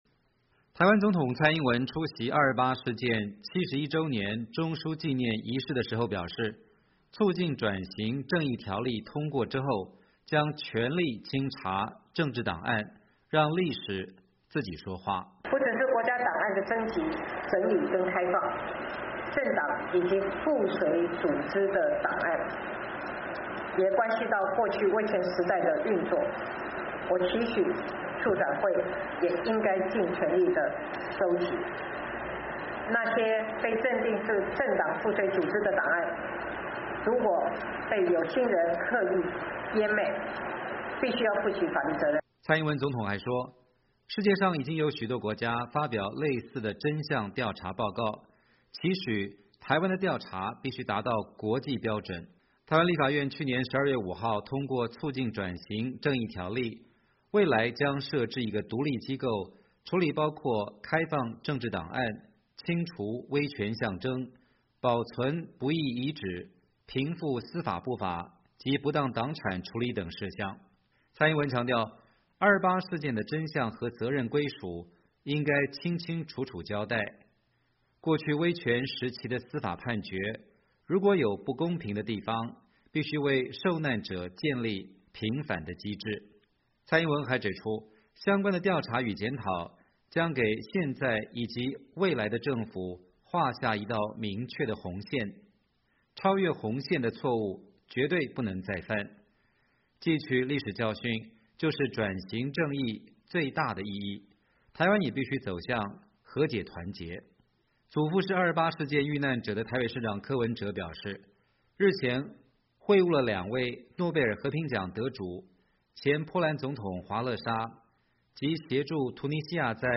台湾总统蔡英文星期三在纪念228事件的活动上表示，期许透过转型正义让台湾走向和解团结。
台湾总统蔡英文出席228事件71周年中枢纪念仪式时表示，促进转型正义条例通过之后，将全力清查政治档案，让历史自己说话。